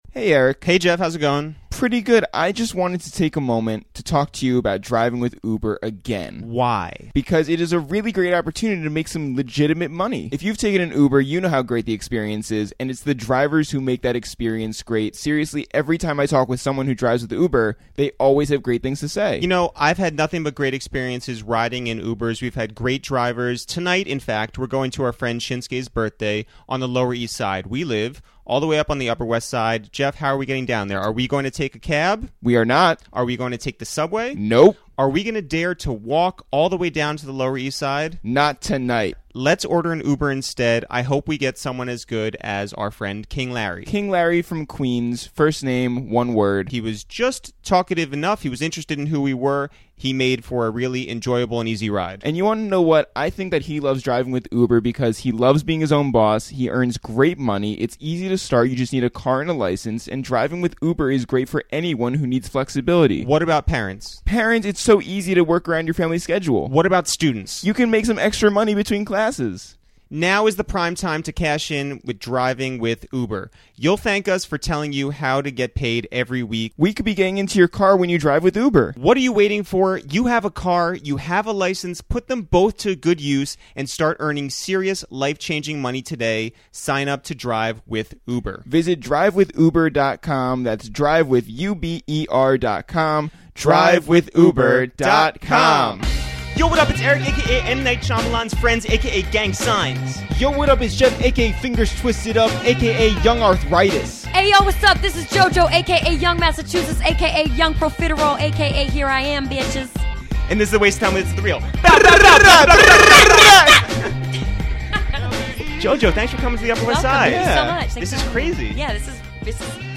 This week, we're joined by the lovely, talented and very funny singer Jojo, who spoke on her nearly decade-long fight with Blackground Records to get out of an unbelievably prohibitive contract, her new start with Atlantic Records, and what exactly a tringle is. We discussed how Maury Povich was instrumental to her success, how to get backstage at a Britney Spears concert, and what it's like when Drake slides into your DMs.